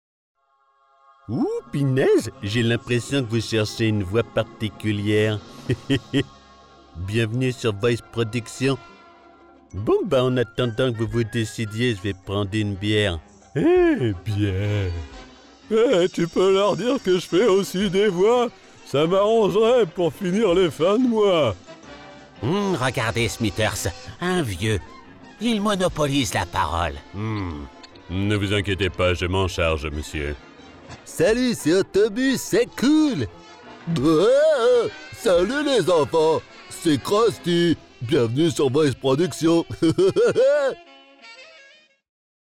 Animation
Bandes annonces
Concernant mon matériel, je possède une cabine vocale et le légendaire micro Neumann U87.